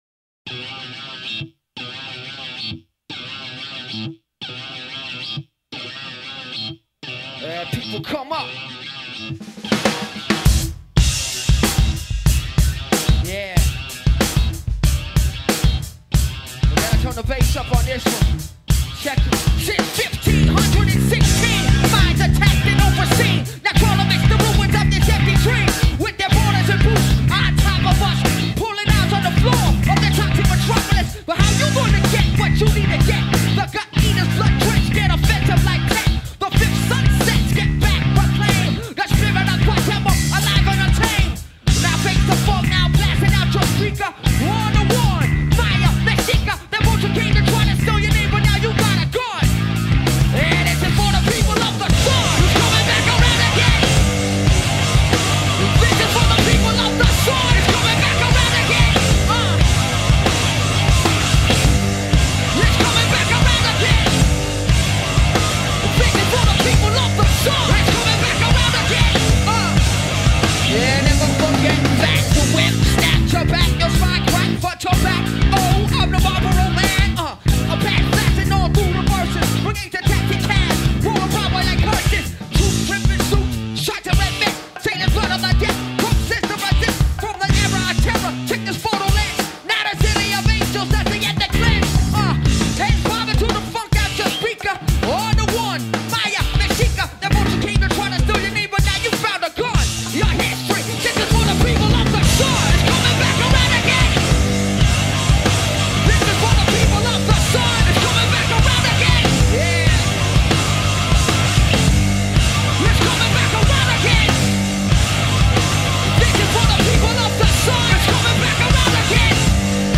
گروه راپ متال آمریکایی
Rap Metal, Political Rock